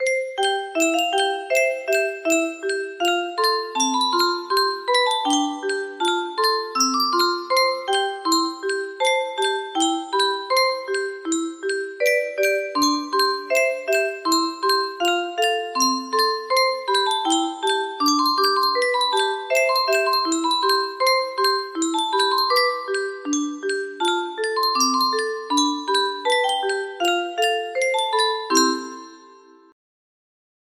Sunny Walk music box melody
Grand Illusions 30 (F scale)